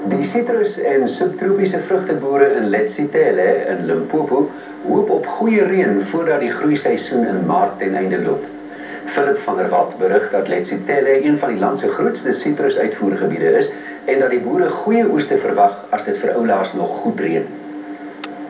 Je to chrchlavý jazyk, ale